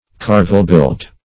Carvelbuilt \Car"vel*built\, a.
carvelbuilt.mp3